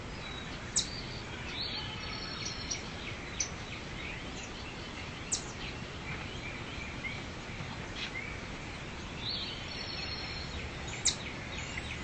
OWI sfx " 吱吱叫的动物
描述：一种微小的动物吱吱声，带有变化。
标签： 小鼠 Yelp的 小动物 佳乐 花栗鼠 动物 变型 大鼠 微型 动物-啸叫 鼠标 啸叫 汪汪地叫 动物声音 小哺乳动物 OWI 呜咽 呜咽 一举超越
声道立体声